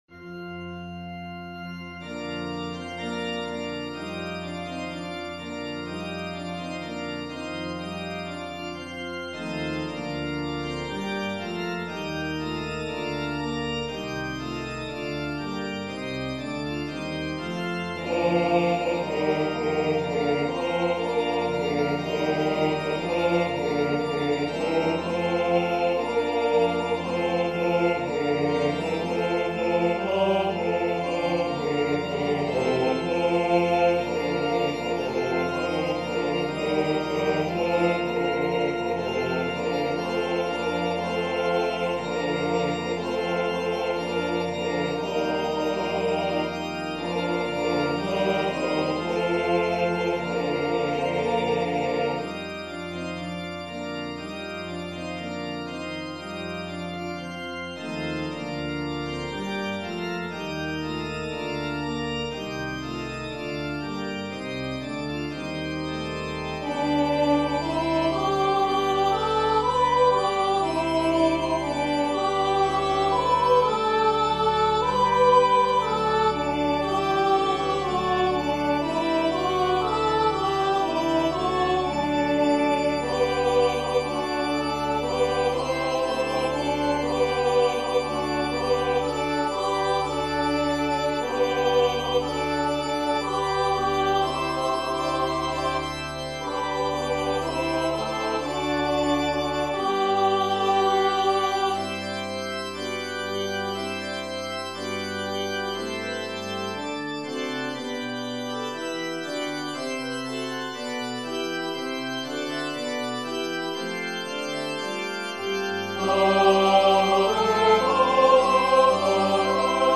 hymn J24 arranged Wilberg